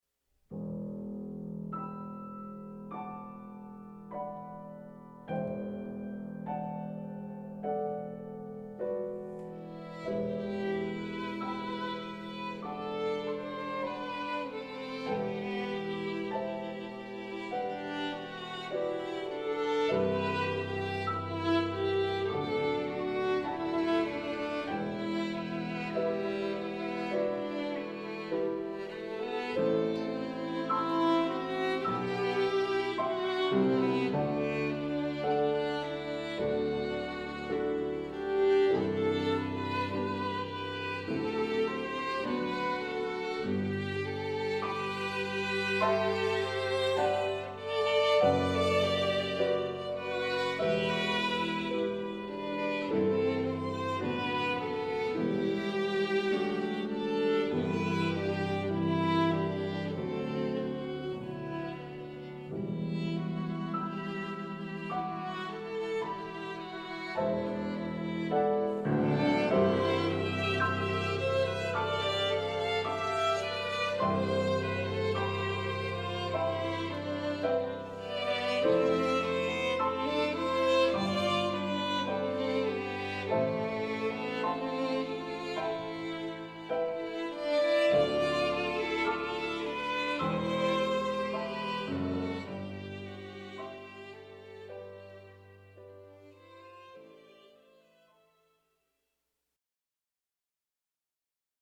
Voicing: 2 Violins